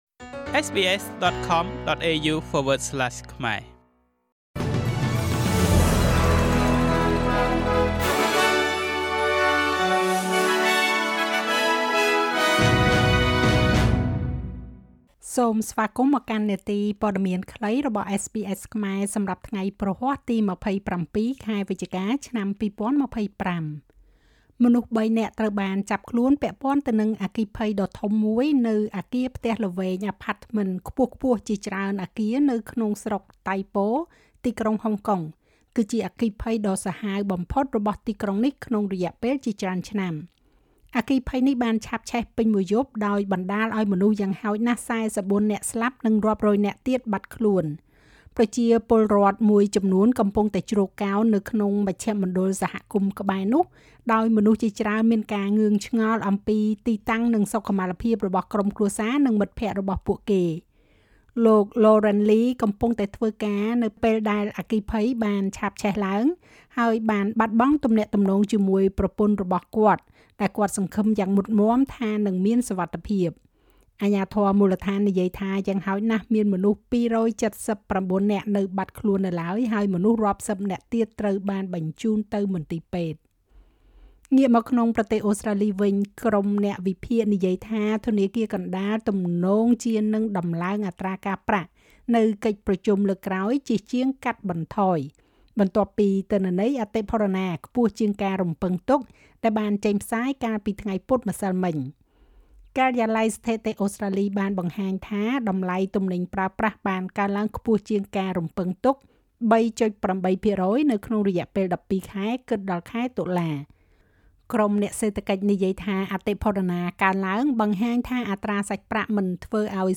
នាទីព័ត៌មានខ្លីរបស់SBSខ្មែរសម្រាប់ថ្ងៃព្រហស្បតិ៍ ទី២៧ ខែវិច្ឆិកា ឆ្នាំ២០២៥